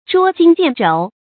注音：ㄓㄨㄛ ㄐㄧㄣ ㄒㄧㄢˋ ㄓㄡˇ
捉襟見肘的讀法